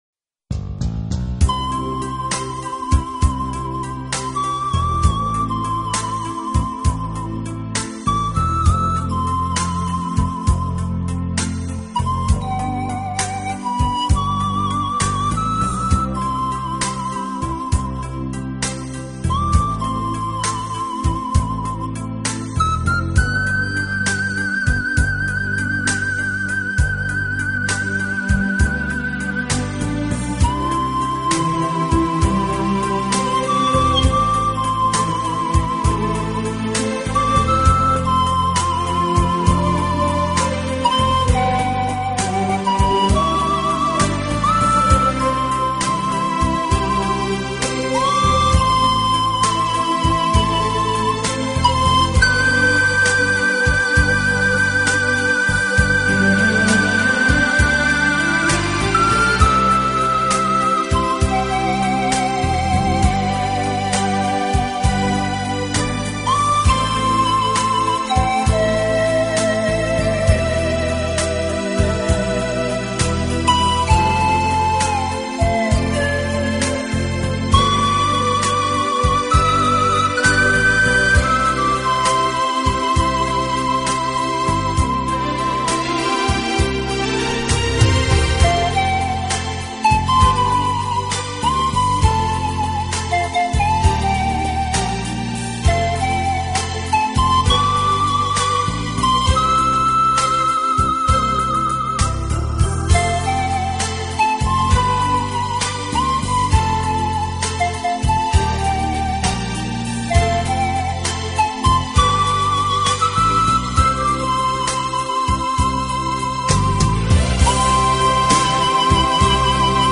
“排笛”这项乐器所发出的音色总带有一种神祕色彩的苍凉